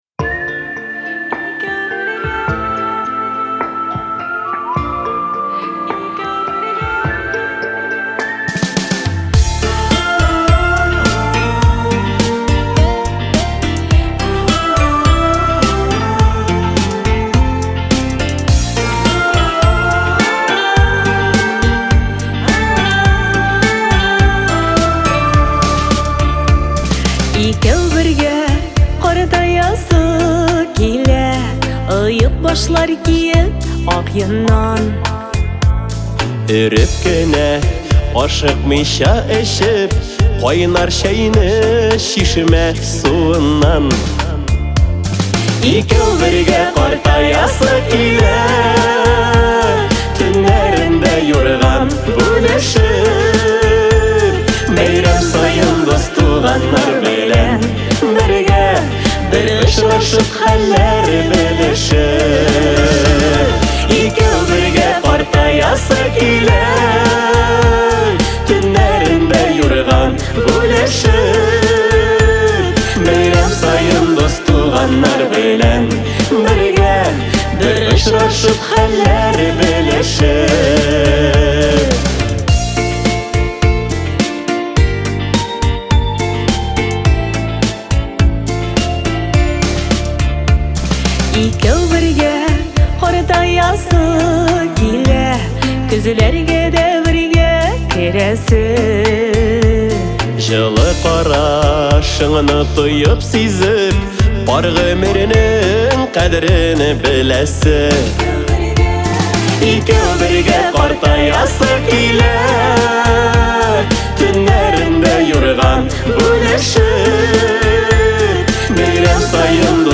это лирическая баллада